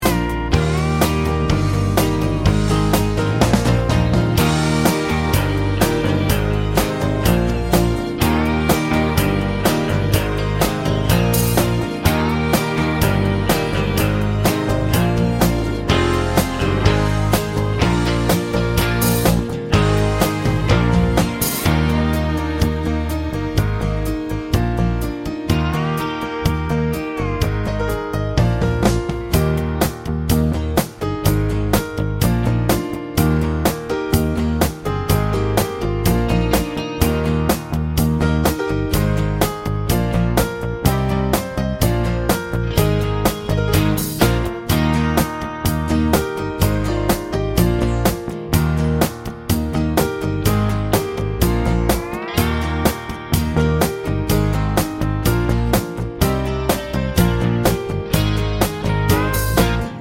no Backing Vocals Country (Female) 4:06 Buy £1.50